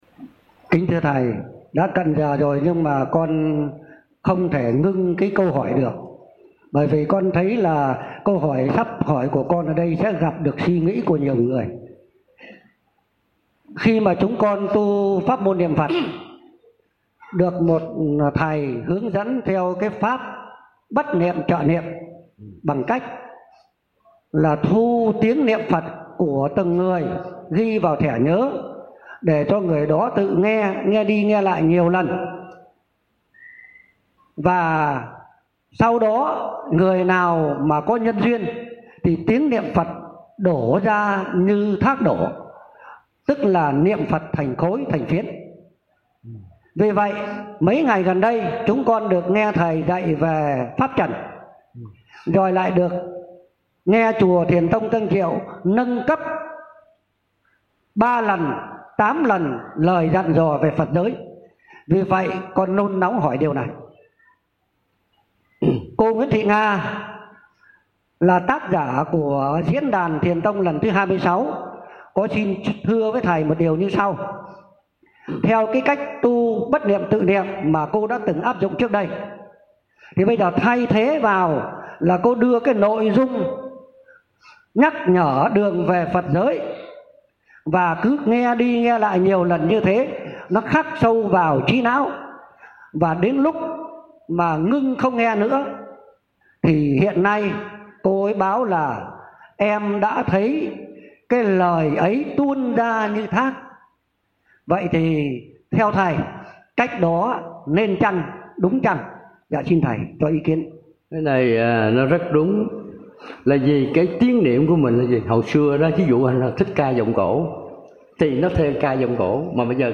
Trò hỏi:
Thầy trả lời: